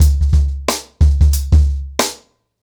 TrackBack-90BPM.27.wav